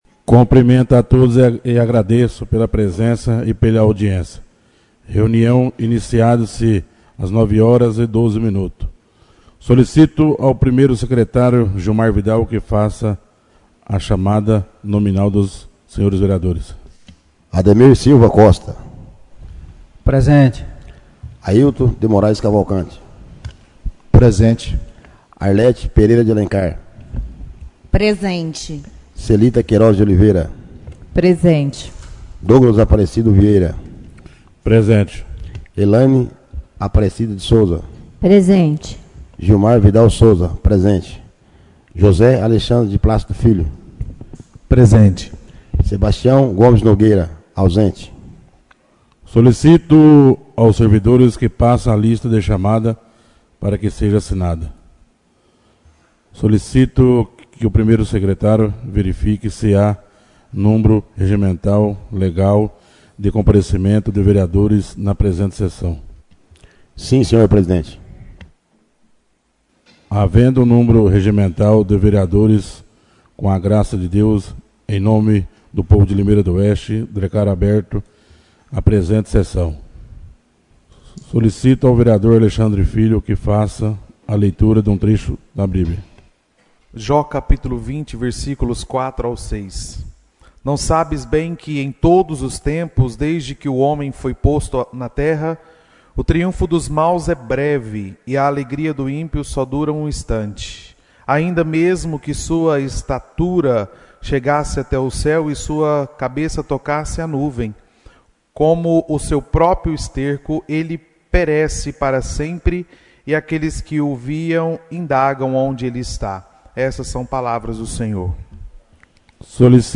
Áudio das Reuniões - 2026